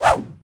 footswing7.ogg